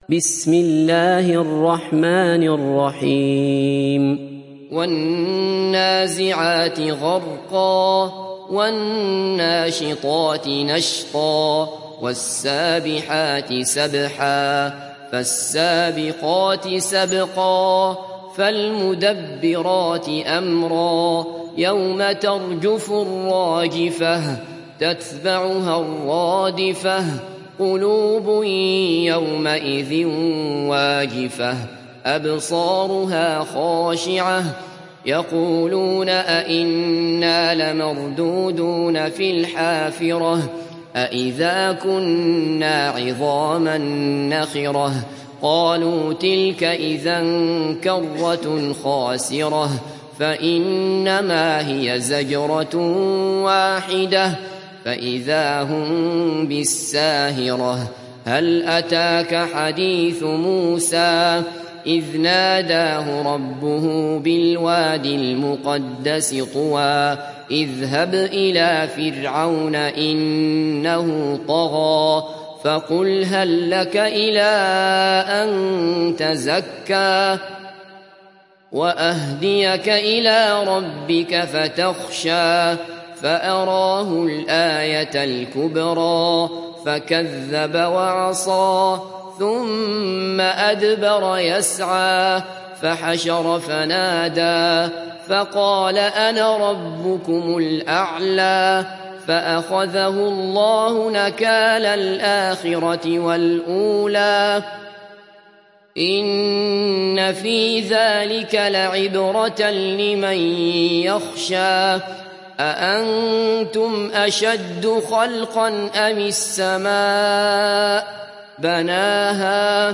Surah An Naziat mp3 Download Abdullah Basfar (Riwayat Hafs)